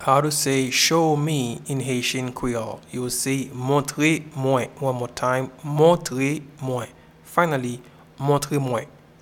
Pronunciation:
Show-me-in-Haitian-Creole-Montre-mwen.mp3